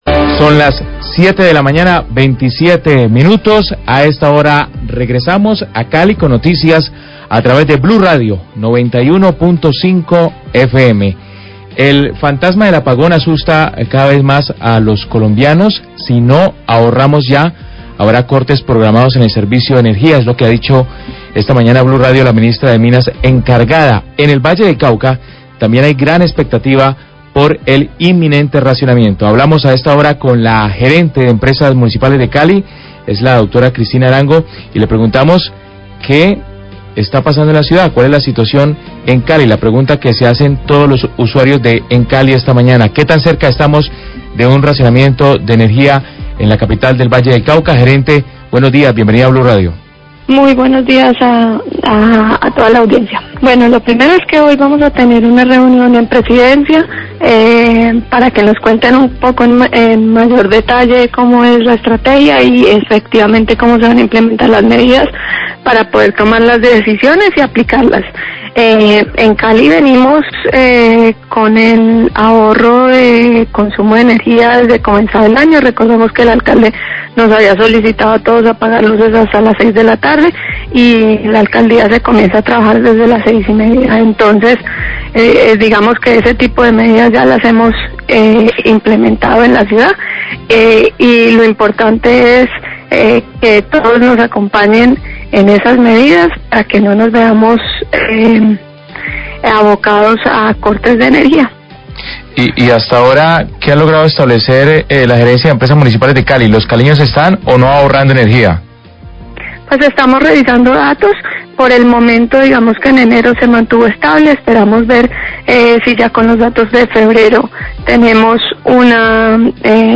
ENTREVISTA A GERENTE DE EMCALI SOBRE RACIONAMIENTO, 7.27AM
Radio